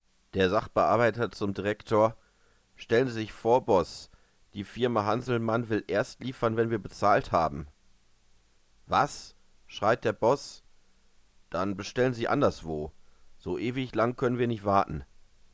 Fun-Blog mit vertonten Witzen, frechen Spruchbildern, Cartoons & Videos